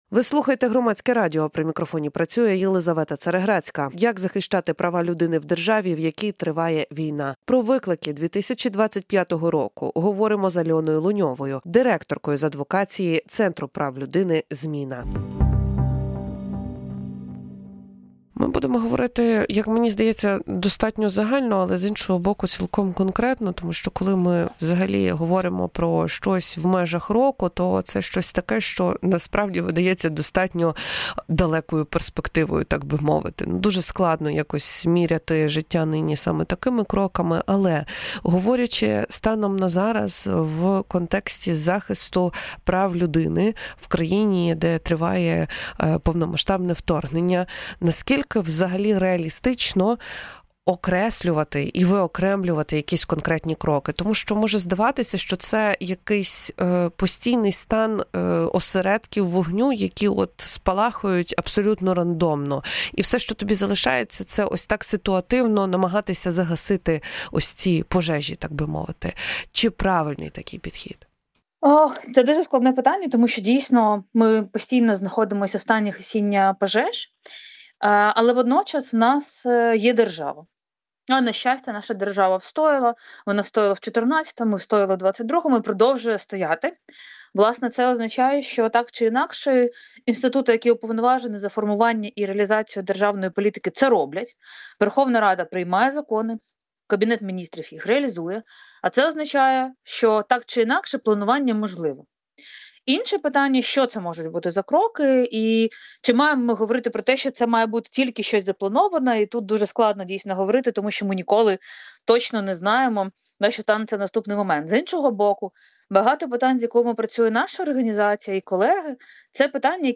Повну аудіоверсію розмови слухайте тут.